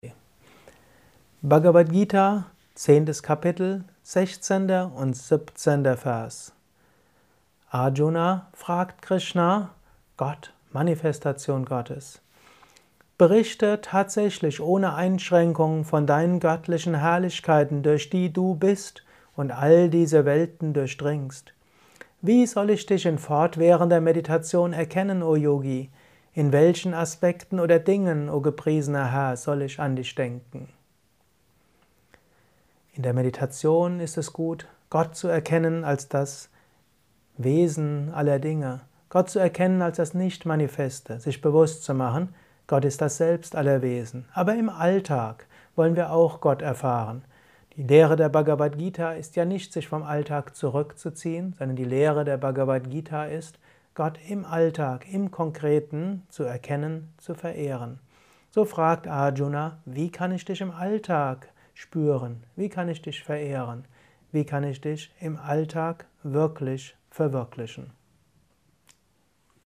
kurzer Kommentar als Inspiration für den heutigen Tag von und mit